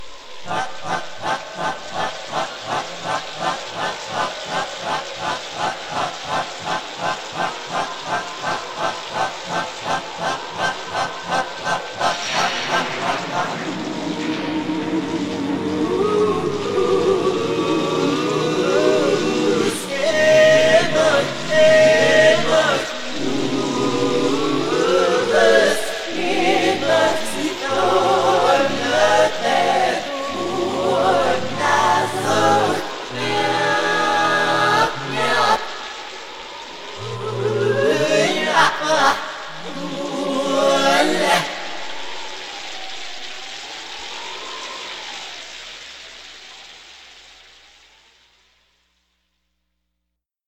Este es el final de la canción en reproducción normal: